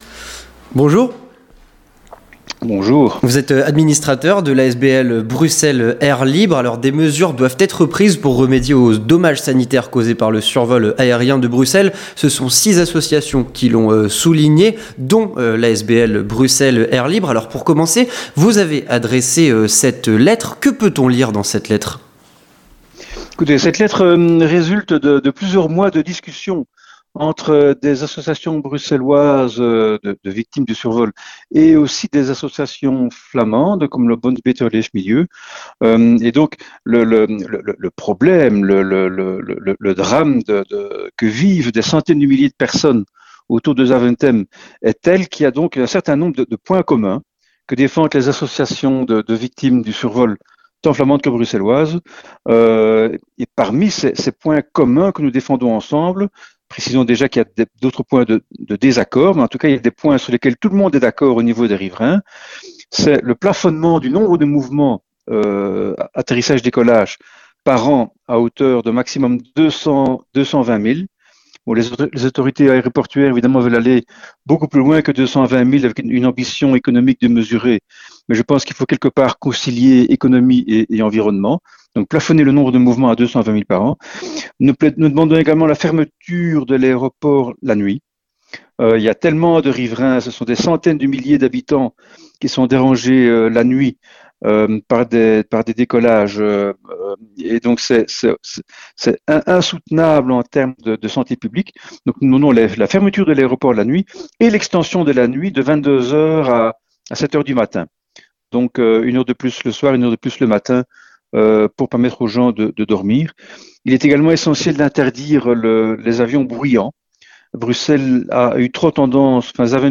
Avec un administrateur de Bruxelles Air Libre